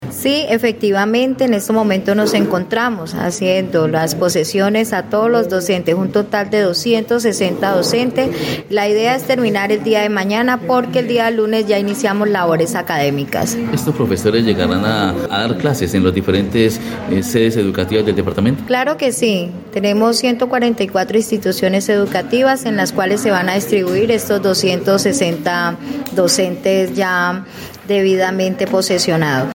Jacqueline Ortiz, secretaria de educación departamental, explicó que el proceso de toma de posesión de estos docentes, deberá culminar lo antes posibles, para que estos lleguen a sus sedes escolares donde empezarán a trabajar sin traumatismo alguno.